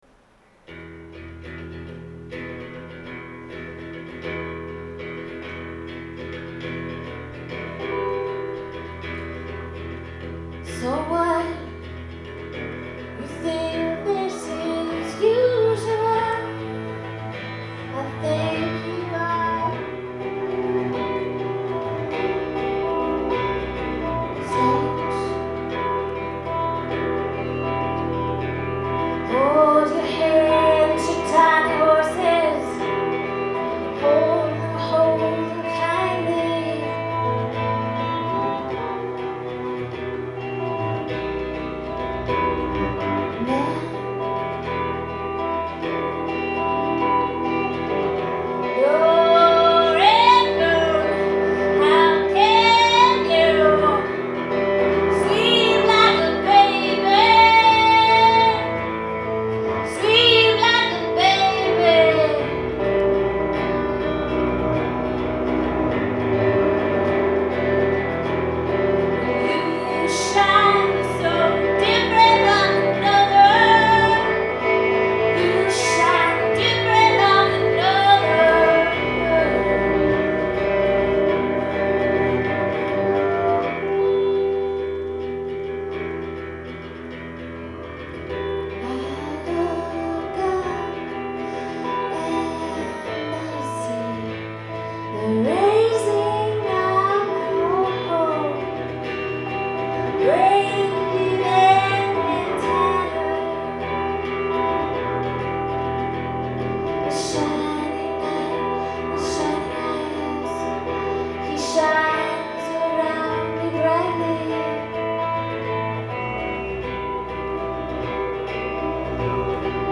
Live at The Brattle Theatre
in Cambridge, Massachusetts